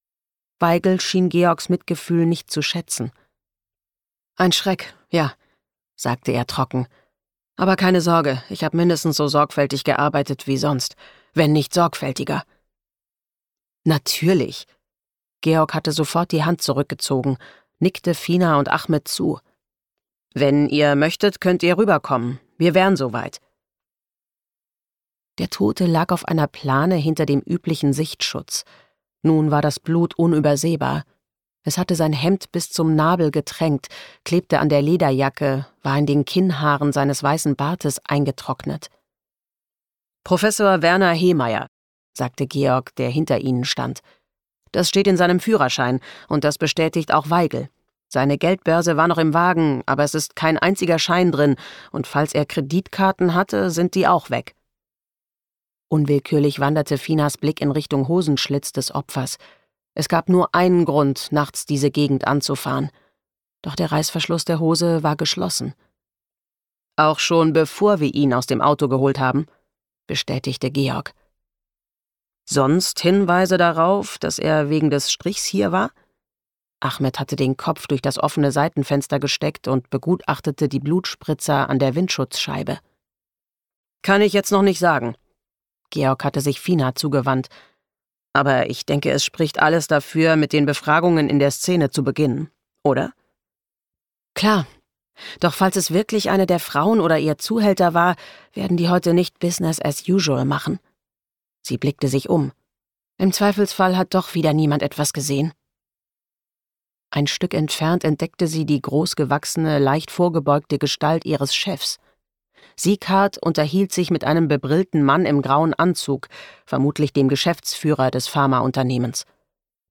Julia Nachtmann (Sprecher)
2025 | Gekürzte Lesung